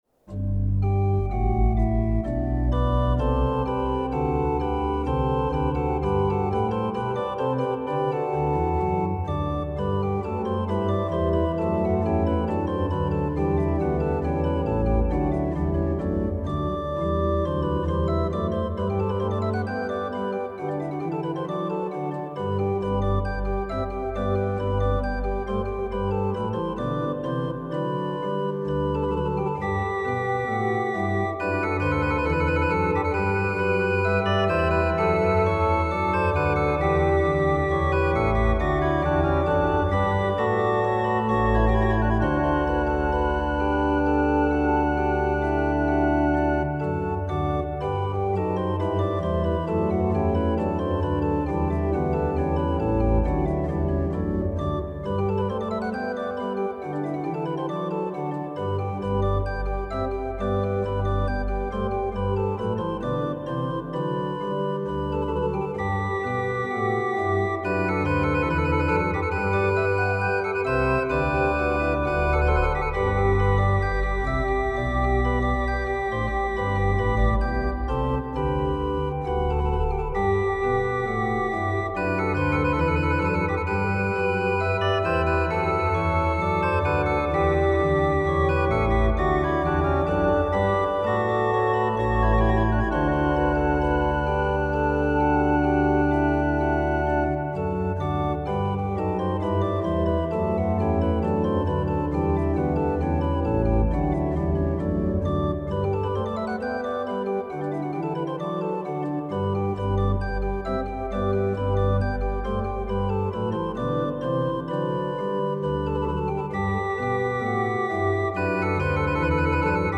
Krebs traite ce choral ligne par ligne. Joué avec grâce et tout de même reconnaissable. L’accompagnement fait clairement entendre l’appel du texte à nous réjouir.
Comme registration, j’ai opté pour des flûtes 8' et 4' en accompagnement et pour un sesquialtera en jeu solo. Et cela à cause de l’équilibre réciproque et de la pureté du cantus firmus.